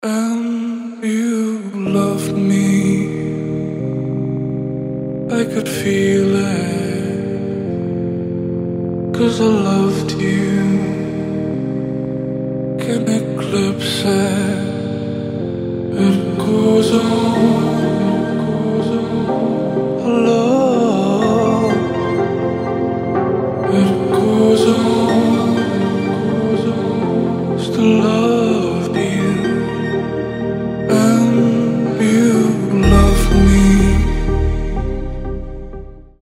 • Качество: 320, Stereo
спокойные
медленные